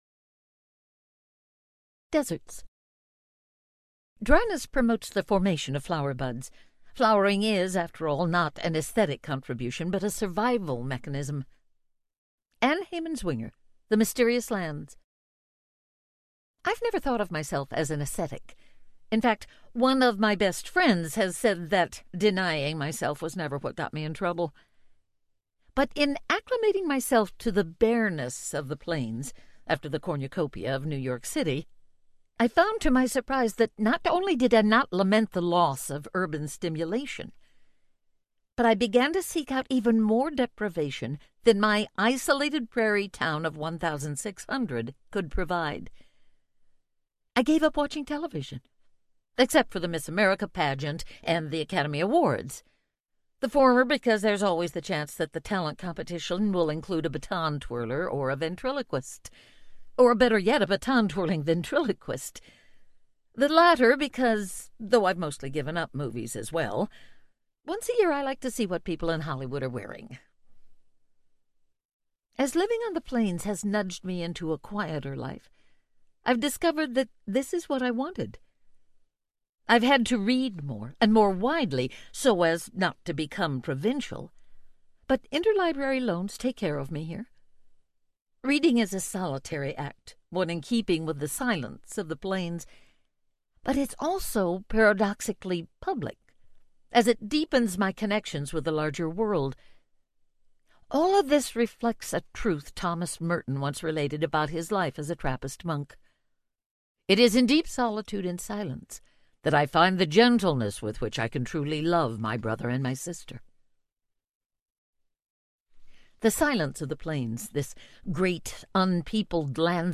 Dakota Audiobook